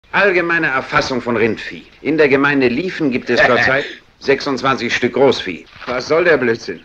Lex Barker: Marco,  Dubbing actor: Peter Pasetti
Sound file of German dubbing actor (108 Kb)